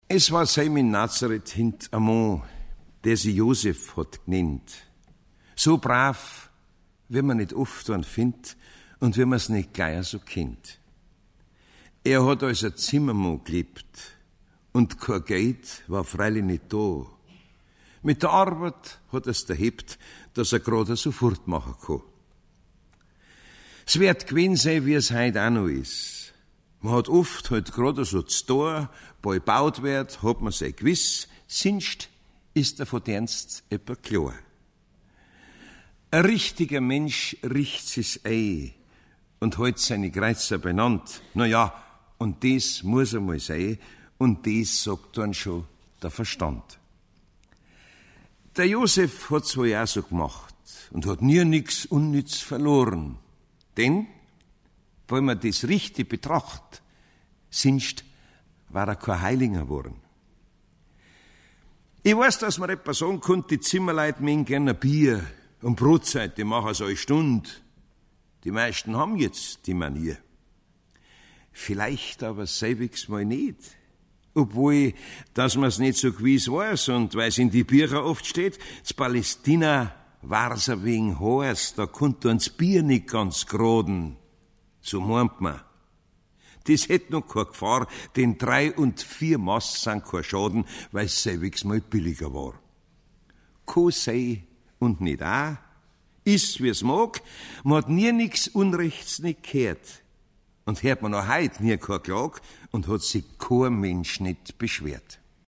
Monika Baumgartner (Sprecher)